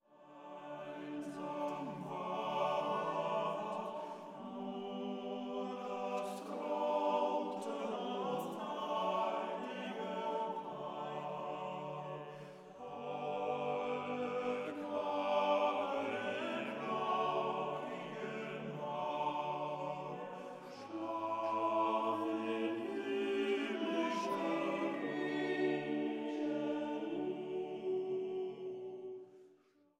Weihnachtliche Chorsätze und Orgelmusik